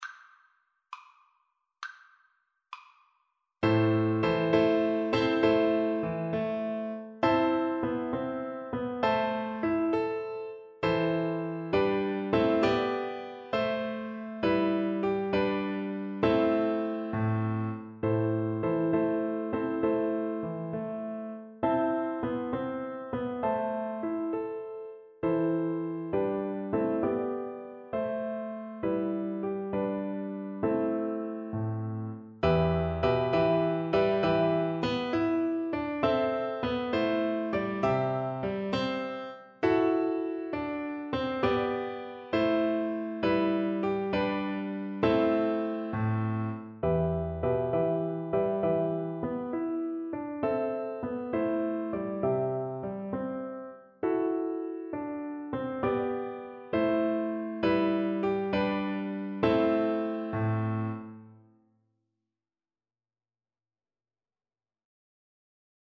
Flute
Traditional Music of unknown author.
6/8 (View more 6/8 Music)
A minor (Sounding Pitch) (View more A minor Music for Flute )
Gracefully
upon_a_summers_day_FL_kar1.mp3